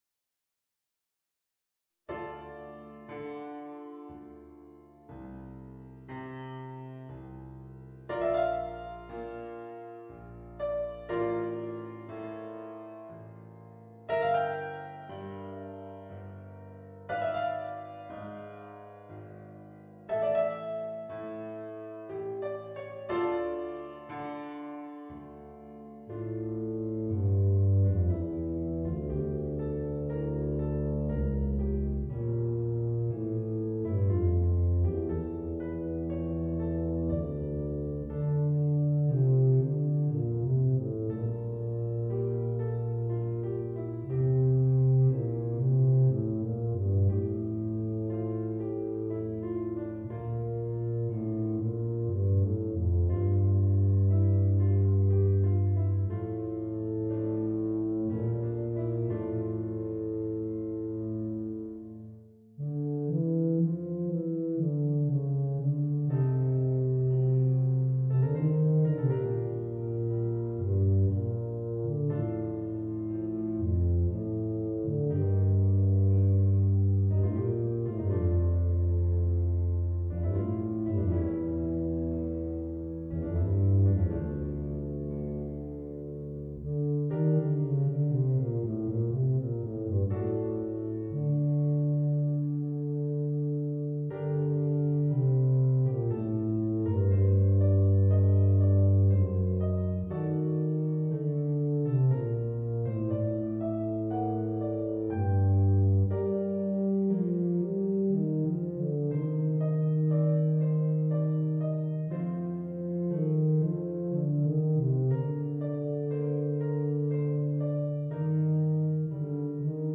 Bb Bass and Organ